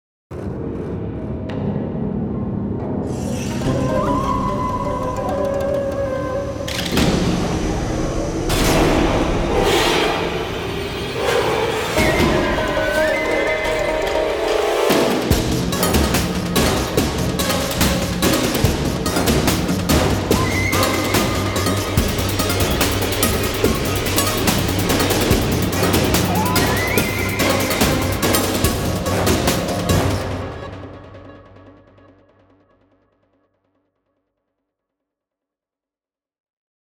Action track for transition & stingers and fighting.